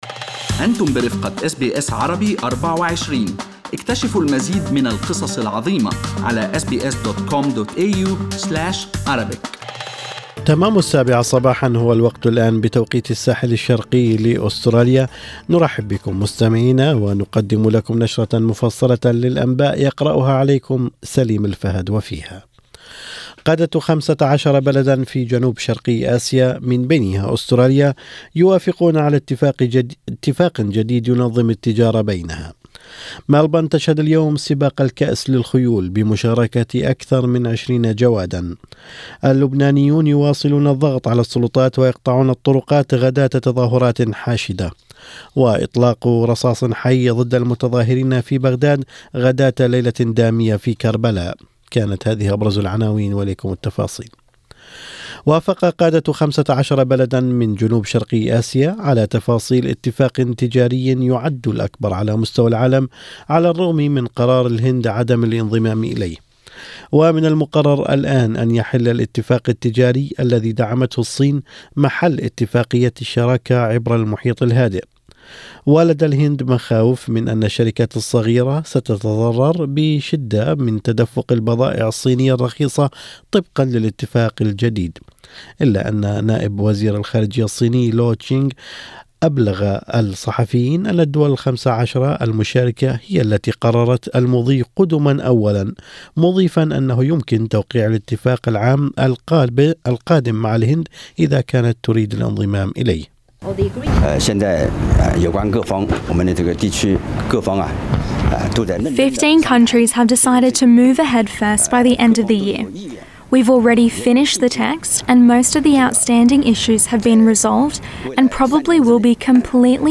أخبار الصباح: توقيع أكبر اتفاق تجارة حرة في العالم يشمل أستراليا ونيوزيلندا والصين